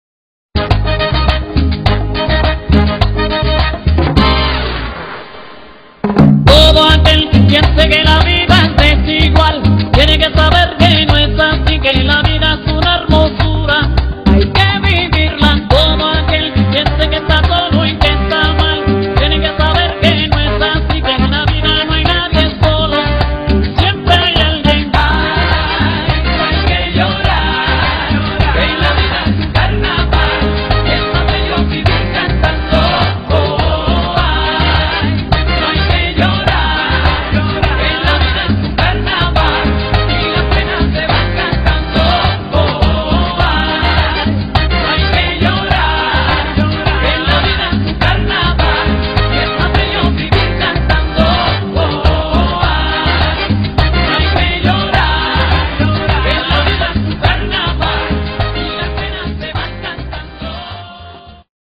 如题，这首欧美歌曲作为ZUMBA（尊巴）舞曲，是我从一段试听曲中截取的，不完整，哪位知道它的名字？